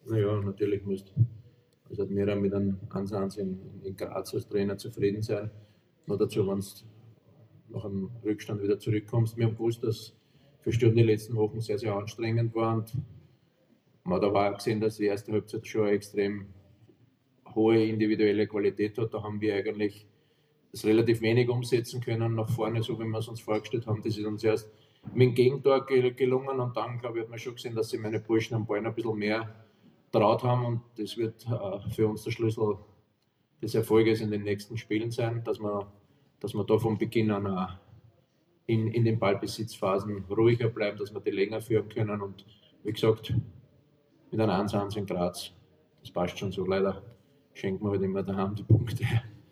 Die Analyse des Gäste-Trainers: